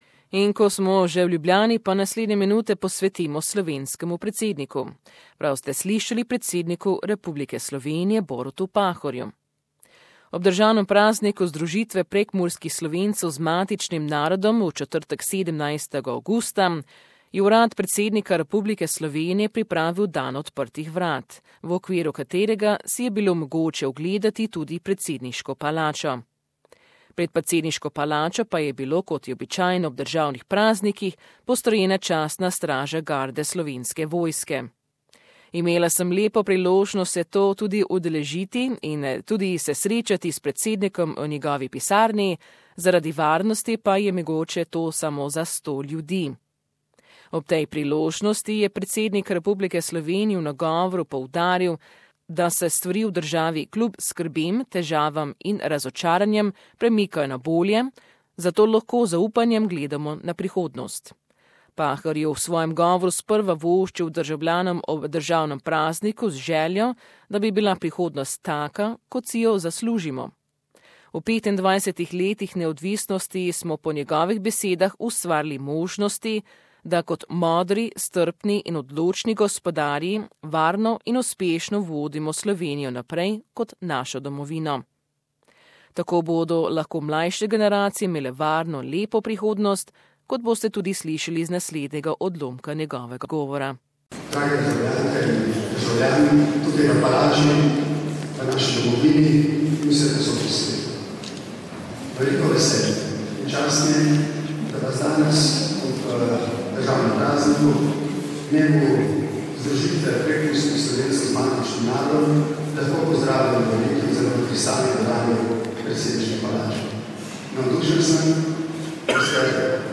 Prekmurje Reunification Day on August 17th remembers the return of the north-eastern region to Slovenia after WWI. The Slovenian President Borut Pahor marked the occasion that day with an Open Day at the Presidential Palace in Ljubljana, which only 100 people can attend due to security and I was lucky enough to be one of them.Following President Pahors speech in which he welcomed all celebrating the occasion, there was a short cultural program in the main hall. This was followed by the chance for all visitors to tour the Presidents office whilst he explained what his day consists of, paintings and knick knacks he has around his desk and then a short Q&A.